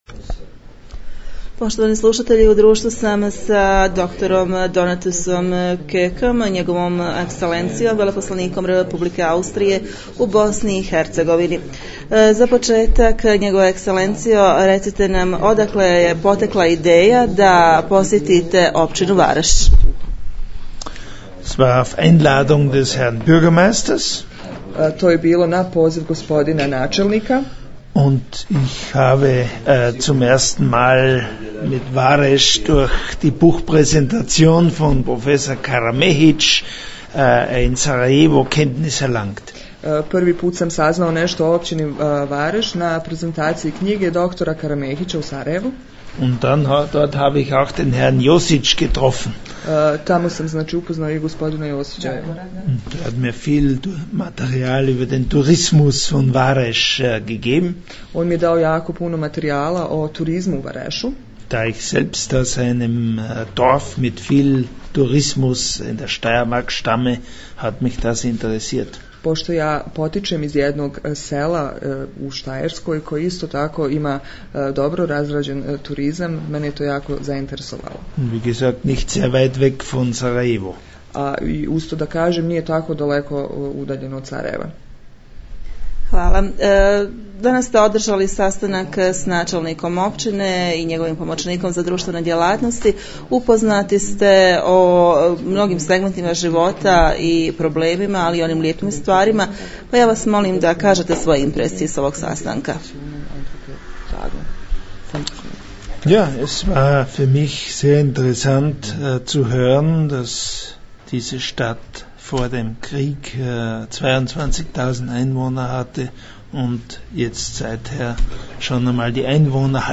Uz članak o posjeti veleposlanika Austrije poslušajte i interview koji je dao za naš medij.....